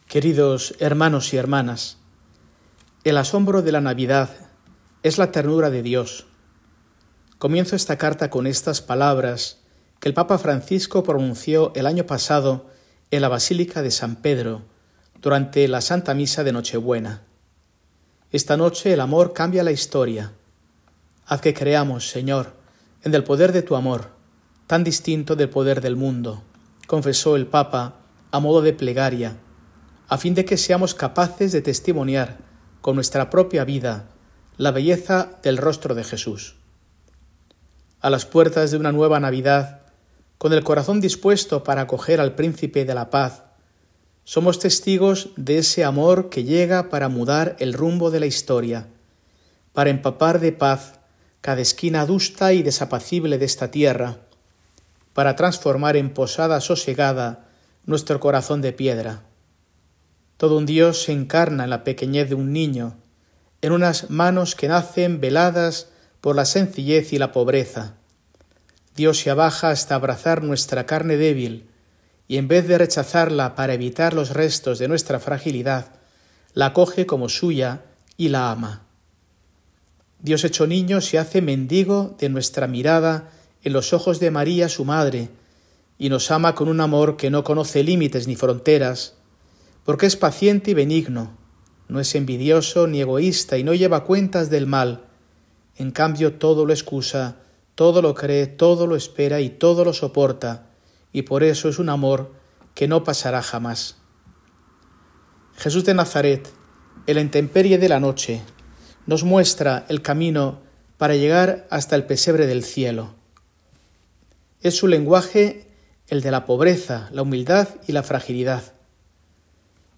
Mensaje de Mons. Mario Iceta Gavicagogeascoa, arzobispo de Burgos, para el domingo, 22 de diciembre de 2024, IV Domingo de Adviento